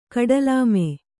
♪ kaḍalāme